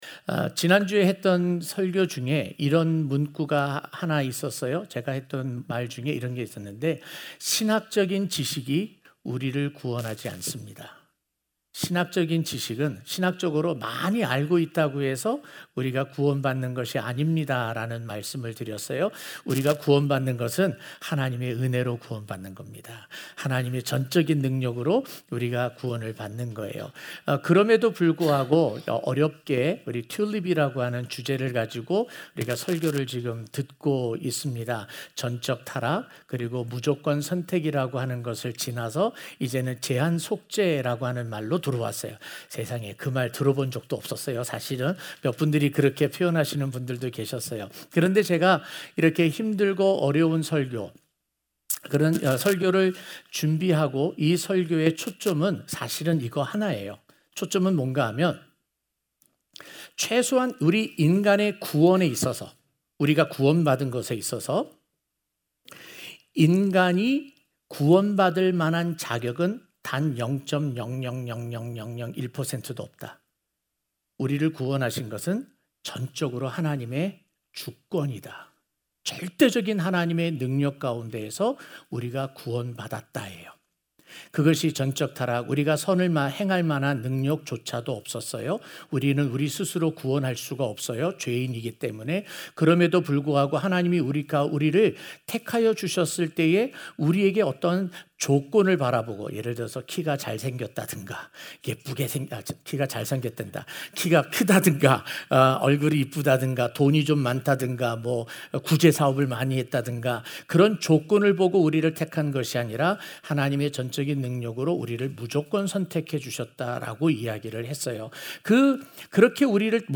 주일설교
03-B-Limited-Atonement-2-Sunday-Morning.mp3